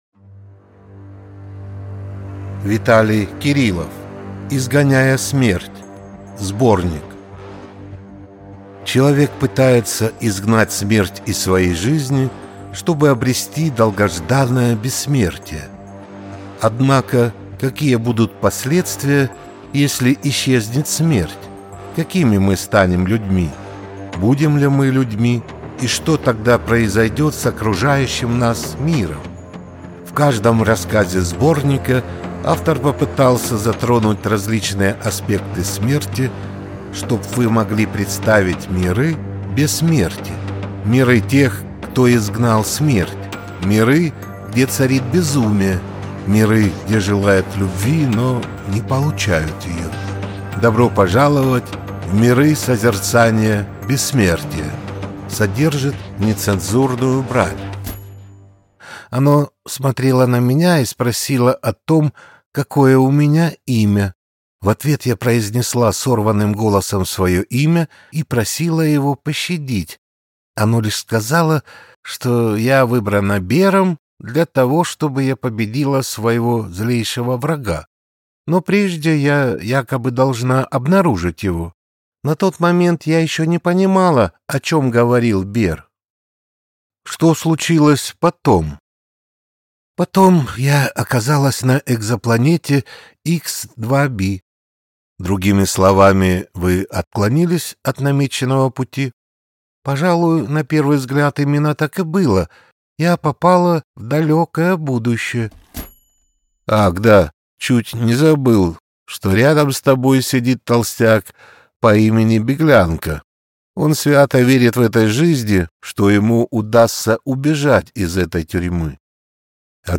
Аудиокнига Изгоняя смерть. Сборник | Библиотека аудиокниг